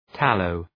Προφορά
{‘tæləʋ}